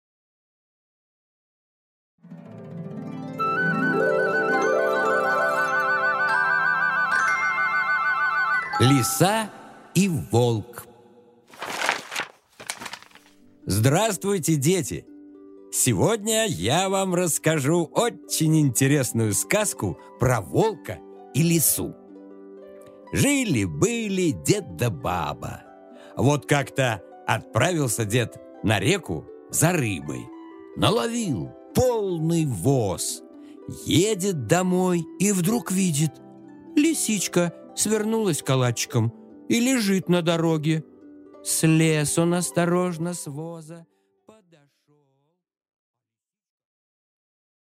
Аудиокнига Лиса и волк | Библиотека аудиокниг
Прослушать и бесплатно скачать фрагмент аудиокниги